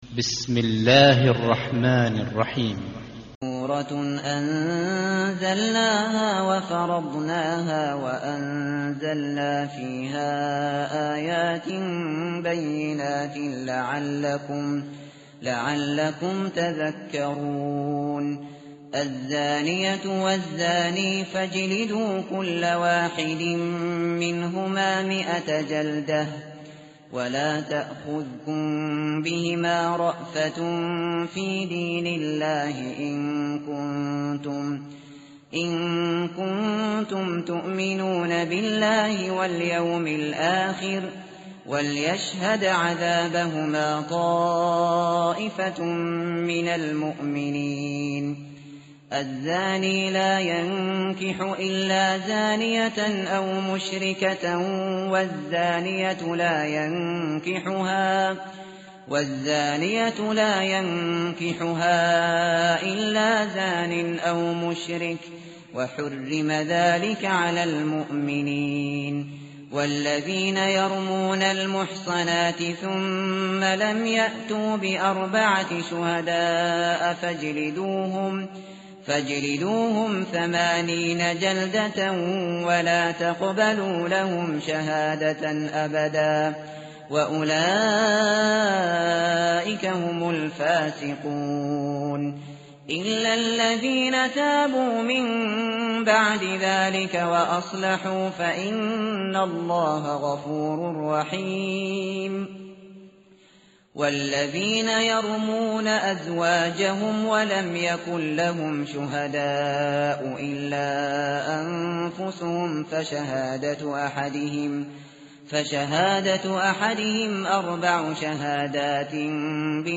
متن قرآن همراه باتلاوت قرآن و ترجمه
tartil_shateri_page_350.mp3